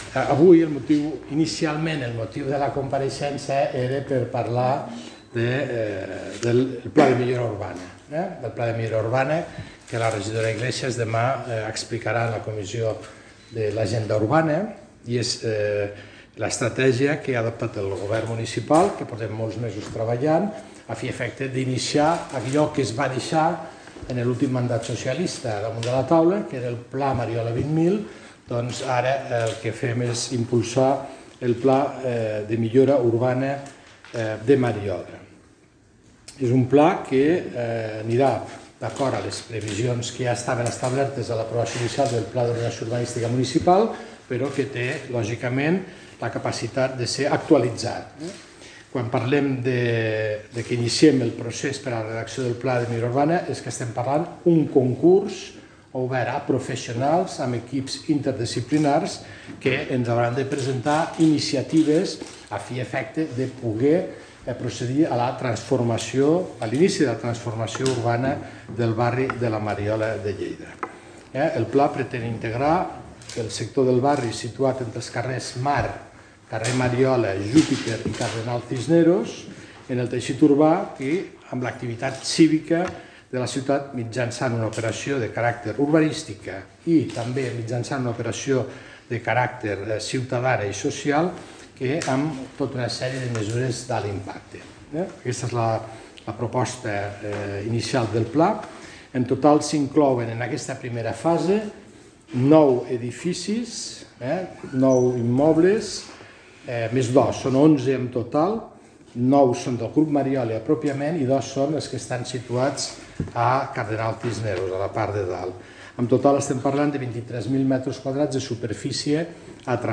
Tall de veu de l'alcalde de Lleida, Fèlix Larrosa, sobre el Pla de Millora Urbana de la Mariola (7.0 MB) Tall de veu de l'alcalde de Lleida, Fèlix Larrosa, sobre l'obertura immediata del Centre Operatiu de la Guàrdia Urbana al centre cívic de la Mariola (9.2 MB)